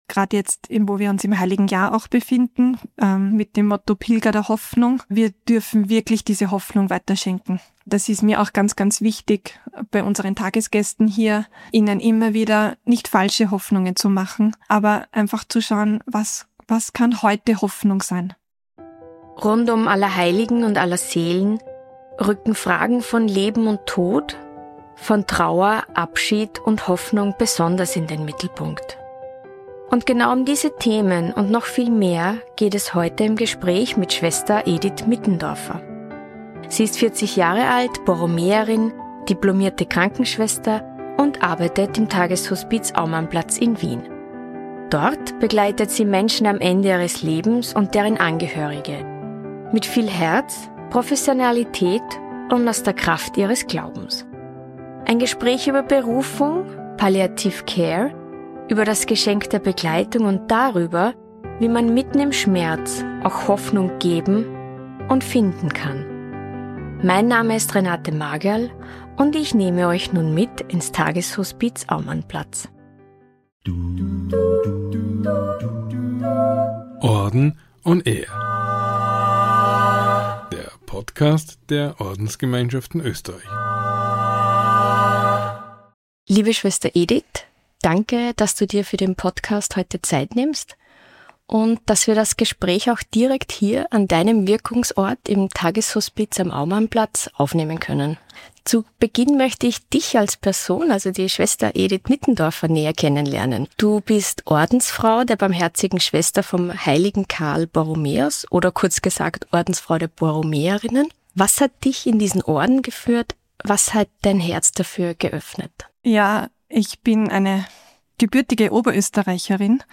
Ein Gespräch über Hospizarbeit, Trauer und die Kraft des Glaubens.